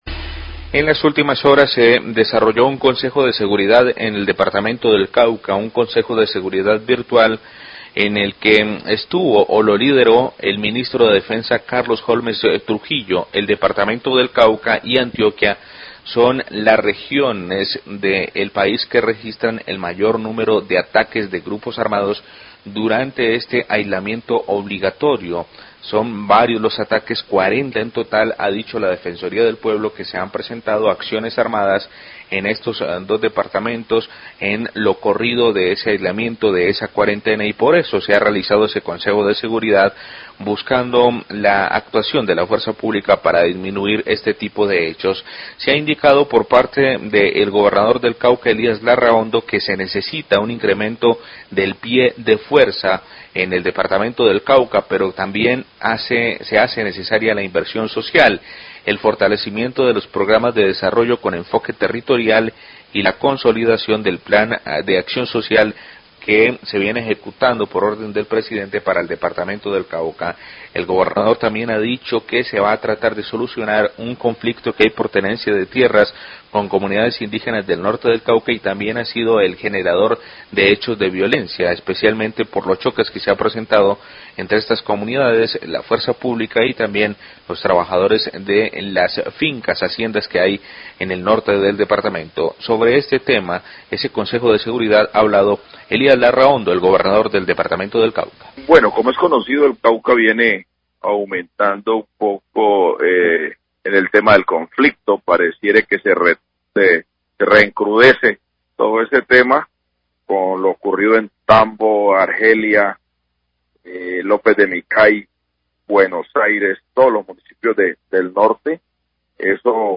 Gobernador Cauca habla de consejo de seguridad por orden público y problemática tierras
Radio
El Gobernador del Cauca, Elias Larrahondo, habla de las conclusiones luego del consejo de seguridad liderado por el Ministro de Defensa donde se estableció una ruta de trabajo entre el Ministerio dle Interior, las comunidades indígenas y los propietarios de cultivos caña de azúcar.